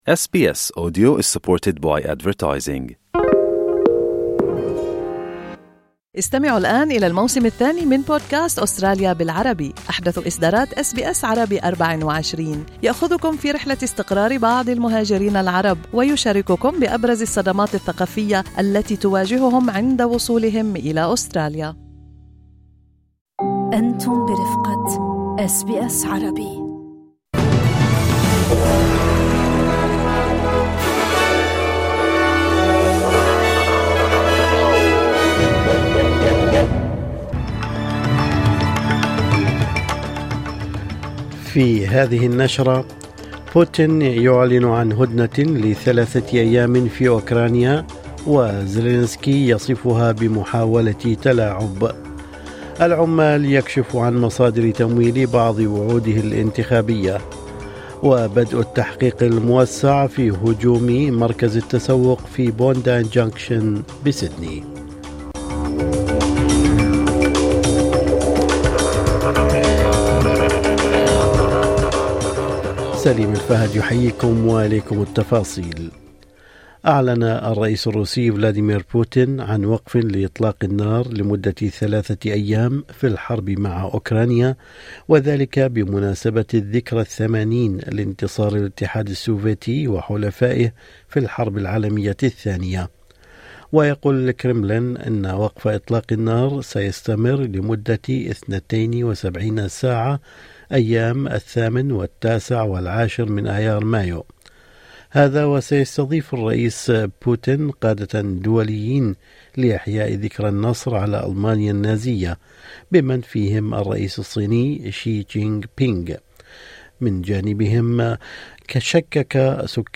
نشرة أخبار الصباح 29/4/2025